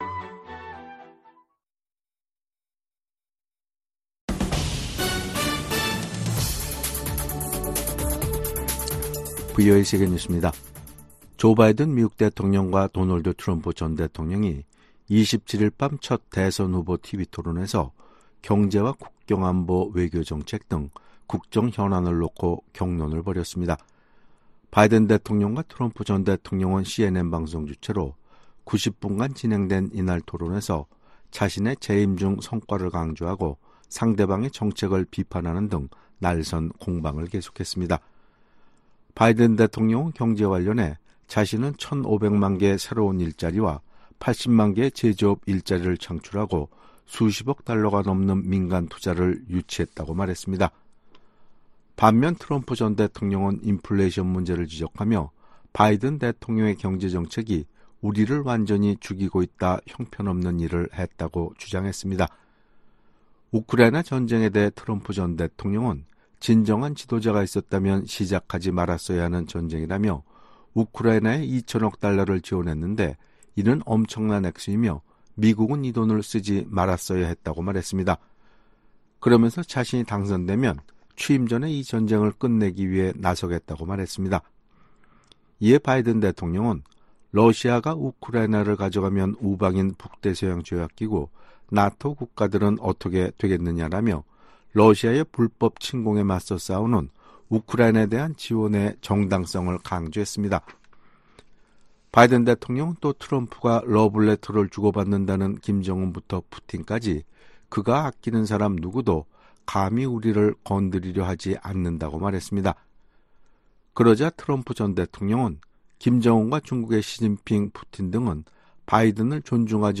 세계 뉴스와 함께 미국의 모든 것을 소개하는 '생방송 여기는 워싱턴입니다', 2024년 6월 28일 저녁 방송입니다. '지구촌 오늘'에서는 국제사회가 주목하는 이란 대통령 보궐선거 소식 전해드리고 '아메리카 나우'에서는 4년 만에 다시 맞붙는 조 바이든 대통령과 도널드 트럼프 전 대통령이 27일 조지아주 애를랜타의 CNN 방송사에서 경제와 낙태, 불법 이민, 외교, 민주주의, 세계의 분쟁과 전쟁 등의 다양한 주제를 놓고 격돌한 소식 전해드립니다.